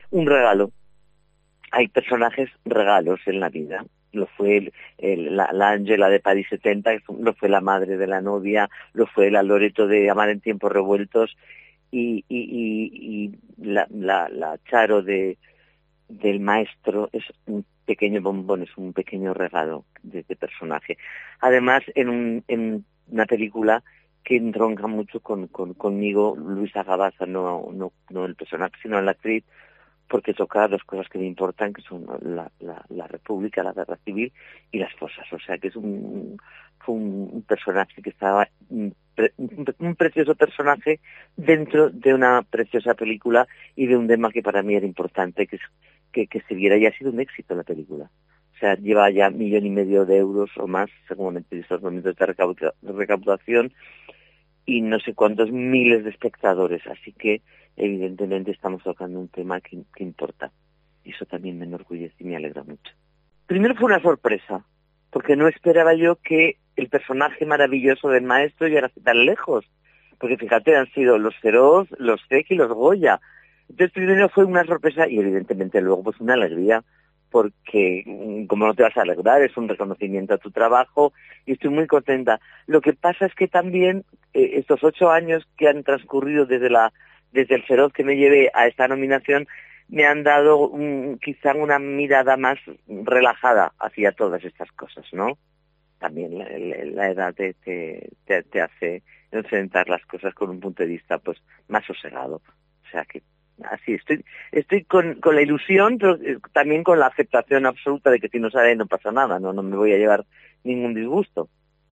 Hablamos con la veterana actriz zaragozana, nominada por su papel en la película de Patricia Font "El maestro que prometió el mar", al Premio Feroz a la Mejor Actriz de Reparto